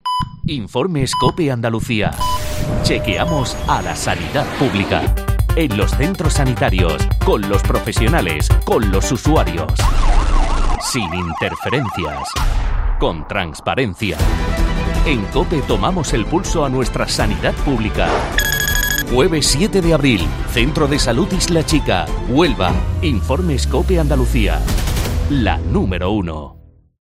Desde las siete de la mañana hasta las ocho de la tarde estaremos en un centro sanitario, realizando toda nuestra programación en directo. Escucharemos a nuestros profesionales.